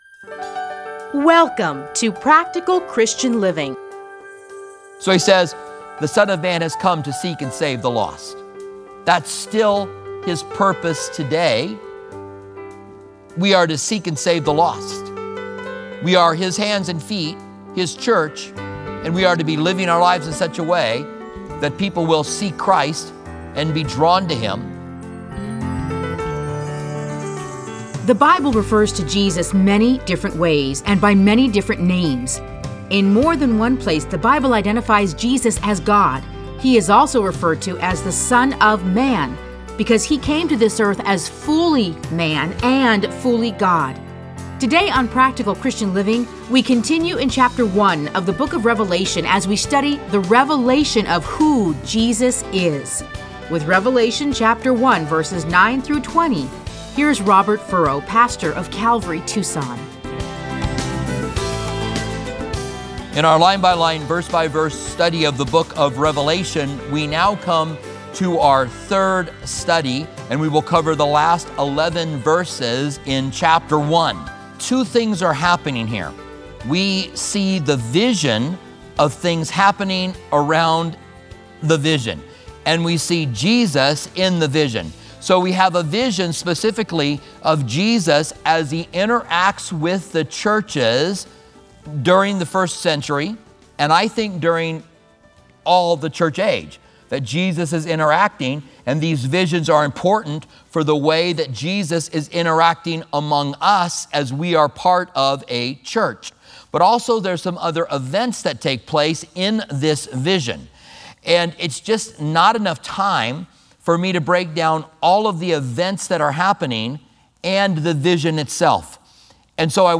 Listen to a teaching from Revelation 1:9-20.